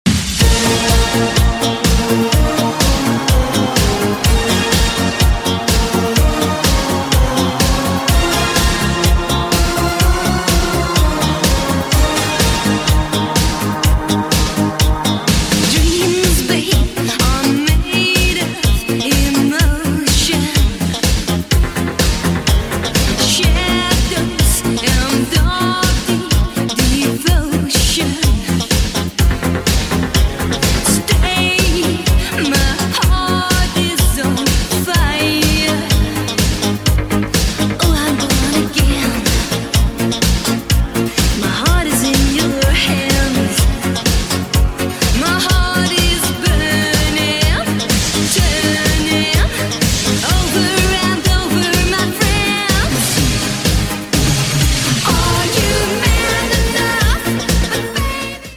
80s Retro Hit